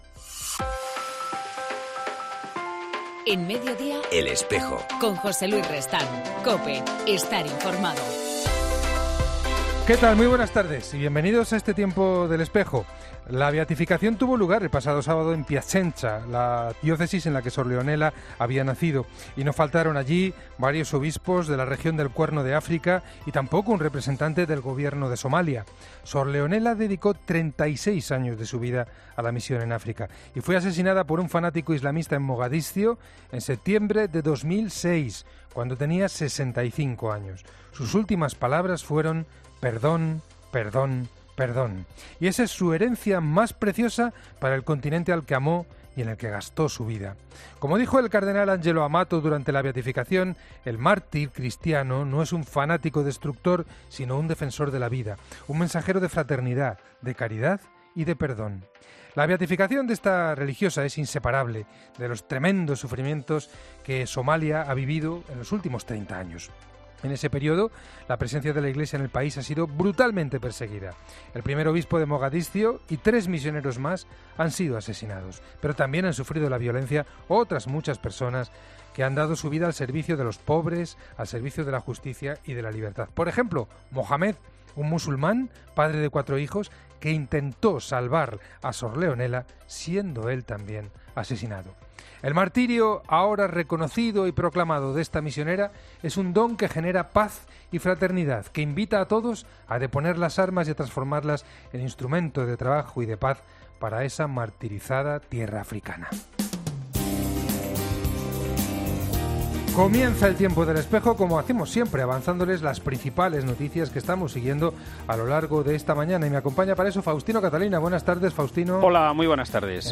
En El Espejo del 28 de mayo hablamos con Mario Iceta obispo Presidente de la Subcomisión de Familia y Vida de la Conferencia Episcopal